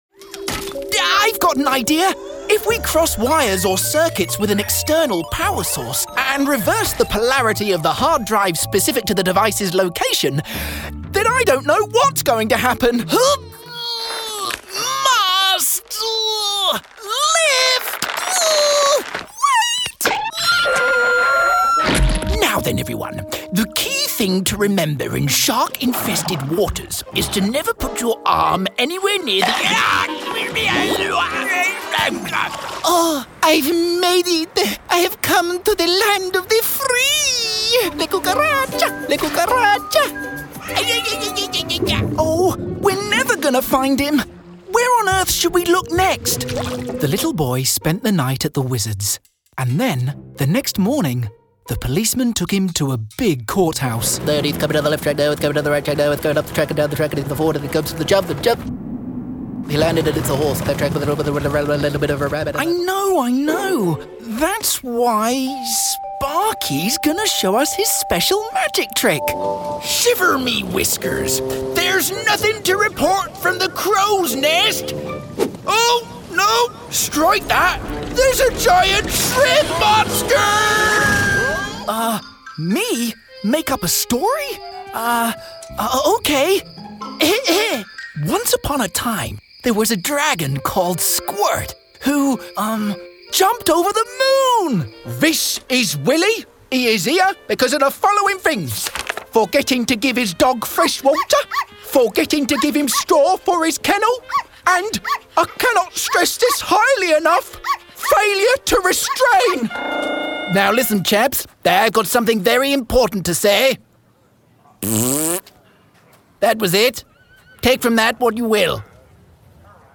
• Native Accent: RP, Welsh
• Home Studio